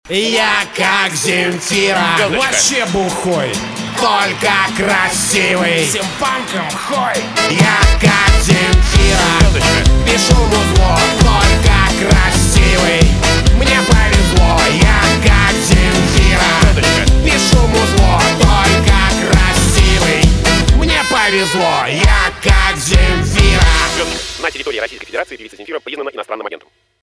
русский рок , барабаны , гитара , труба